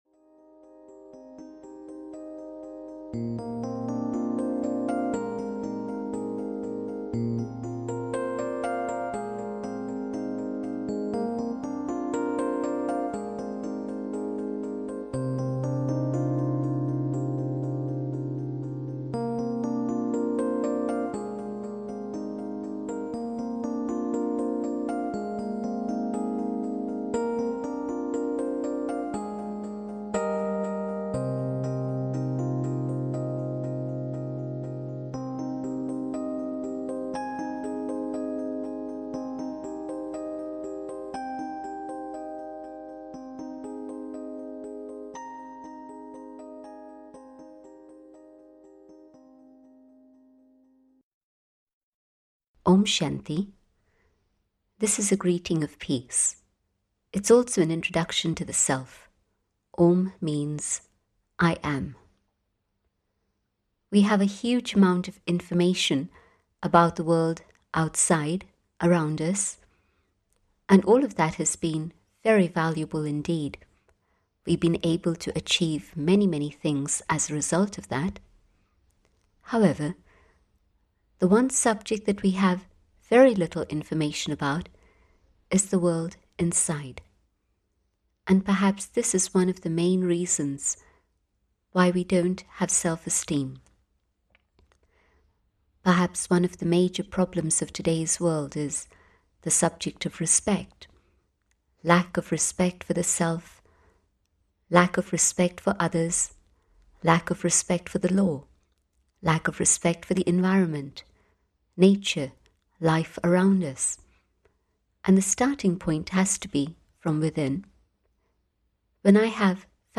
Ukázka z knihy
When we do not know ourselves we cannot respect ourselves, without self-esteem we lack inner strength and lose control over our thoughts, words, and actions, ending up in a state of confusion, guilt, and despair.This talk aims to help reverse this process so we can develop a true sense of the Self and build self-respect and self-esteem